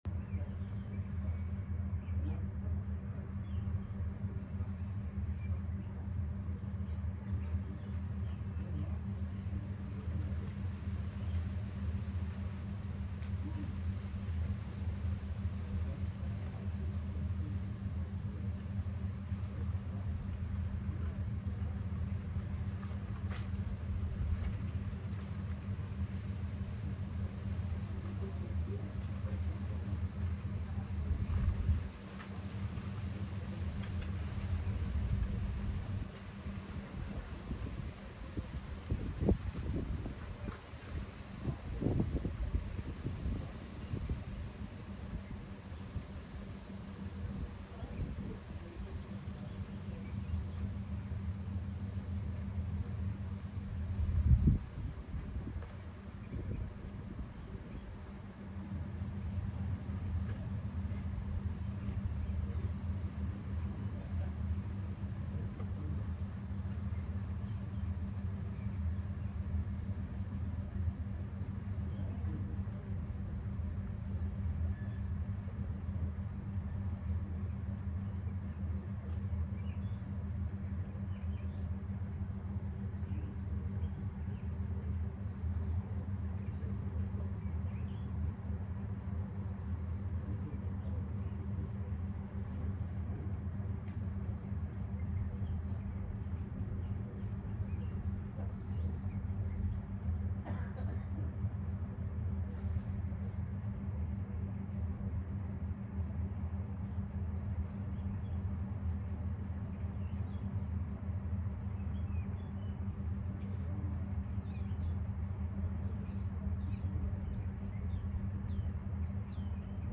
It's a beautiful winter day. I'm sitting outside a soft breeze blowing around me, the dogs are (mostly) out here with me. The ambiance is lovely; quiet for the most part, but very suburban.